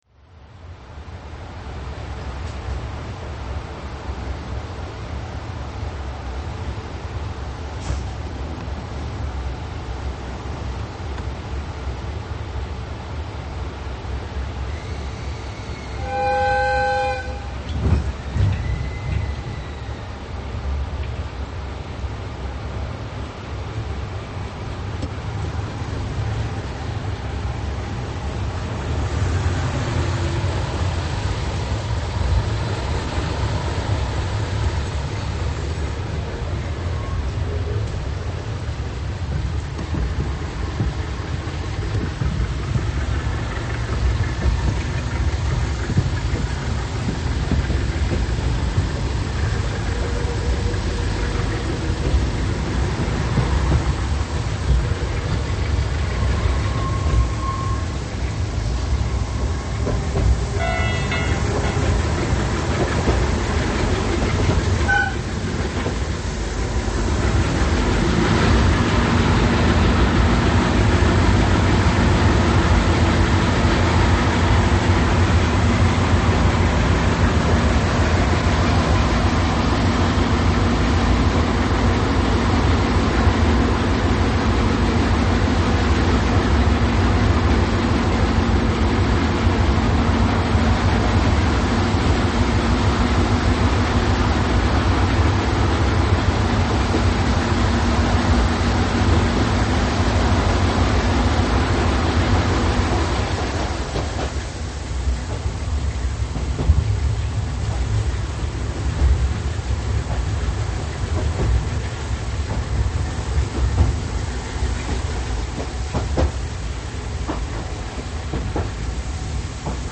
DF50 紀勢本線見老津～周参見間（途中）　昭和49年9月 　　　　　（走行音）MP3　2770KB　6分05秒
機番が分かりませんが、耳がおかしくなるほどの音です。